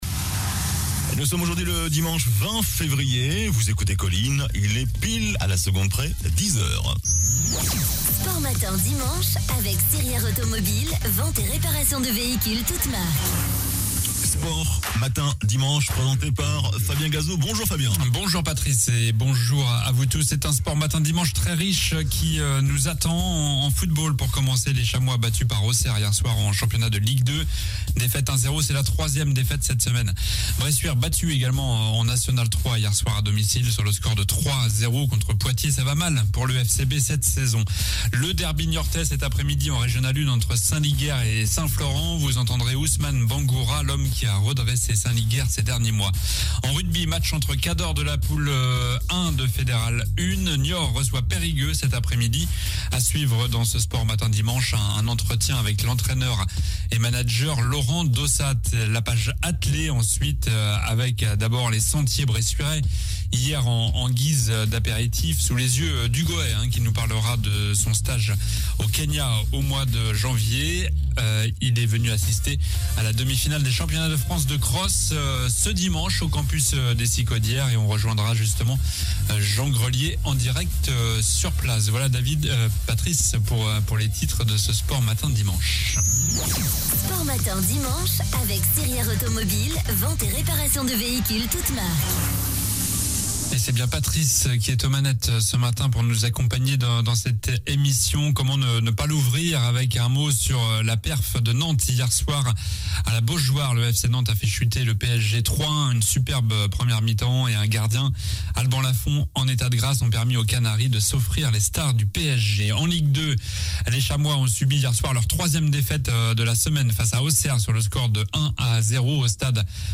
Trail : Reportage sur les sentiers bressuirais